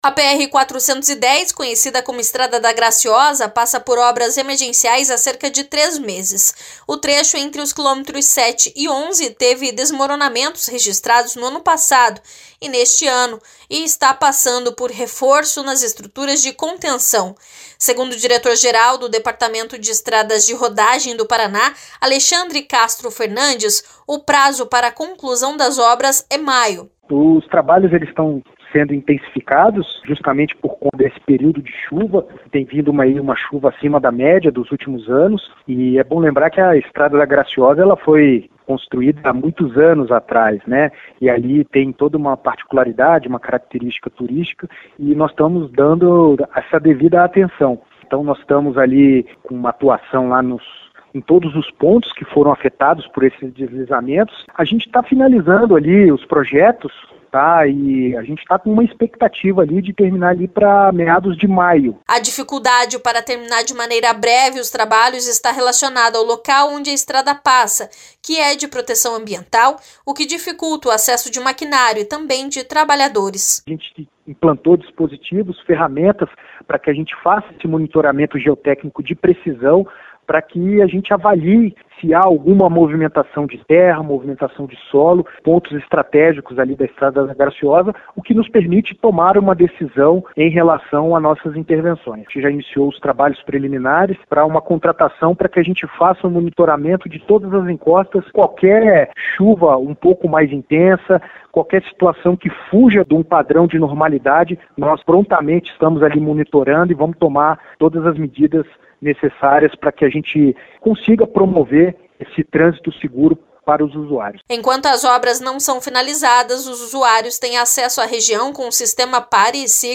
Segundo o diretor-geral do Departamento de Estradas de Rodagem do Paraná (DER/PR), Alexandre Castro Fernandes, o prazo para conclusão das obras é maio.